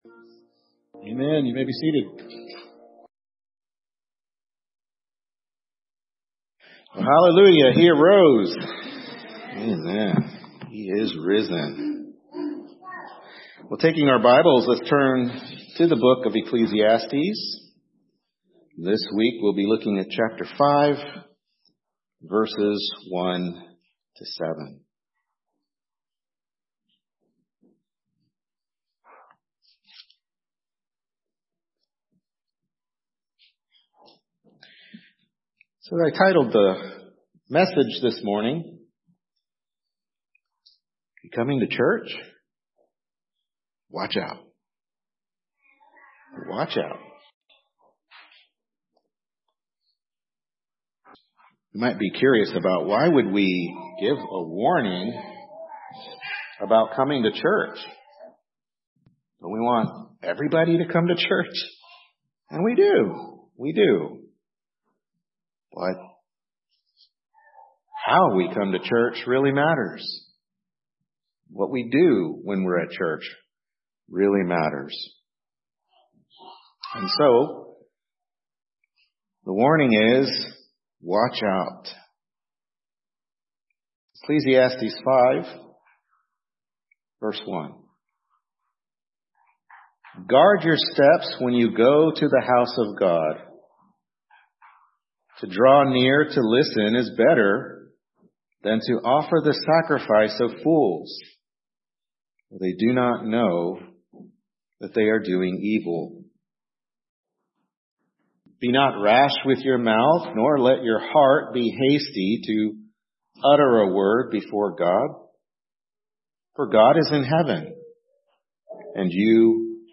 Ecclesiastes 5:1-7 Service Type: Morning Worship Service Ecclesiastes 5:1-7 Coming to Church?